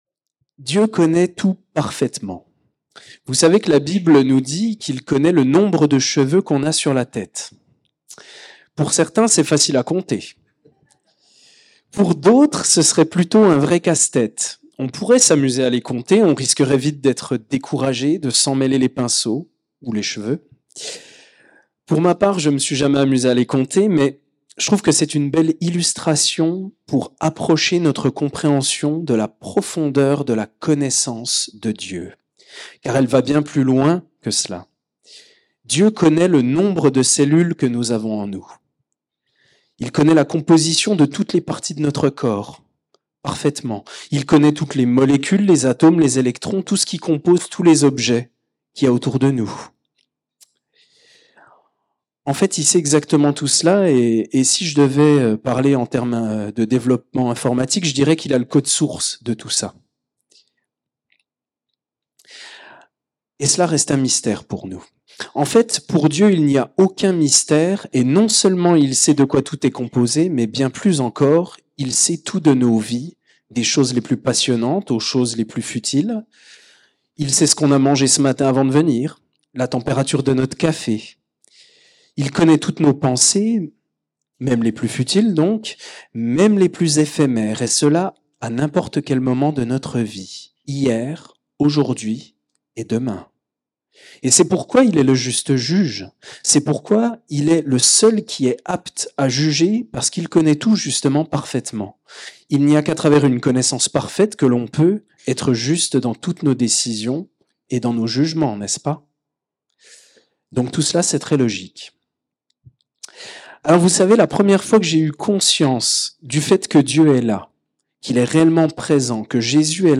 Culte hebdomadaire - EEBS - Église Évangélique Baptiste de Seloncourt